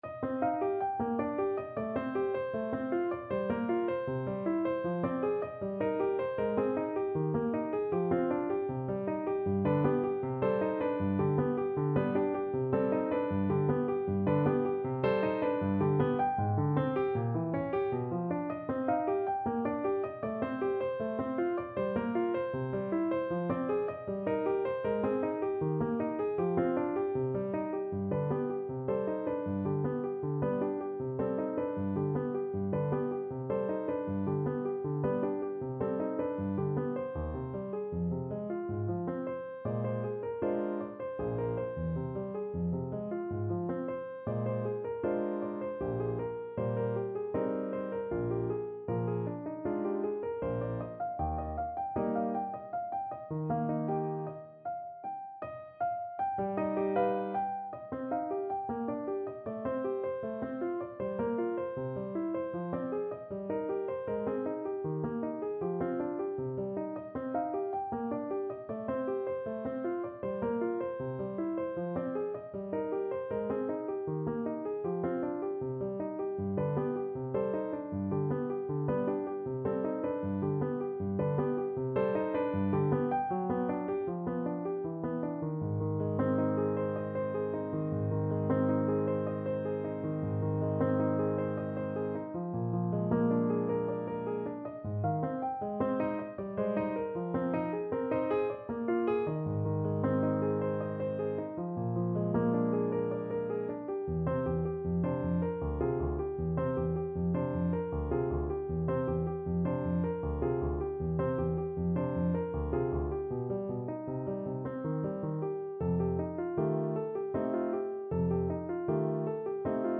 Play (or use space bar on your keyboard) Pause Music Playalong - Piano Accompaniment Playalong Band Accompaniment not yet available transpose reset tempo print settings full screen
3/4 (View more 3/4 Music)
C minor (Sounding Pitch) D minor (Clarinet in Bb) (View more C minor Music for Clarinet )
~ = 100 Andantino quasi Allegretto =78 (View more music marked Andantino)
Clarinet  (View more Intermediate Clarinet Music)
Classical (View more Classical Clarinet Music)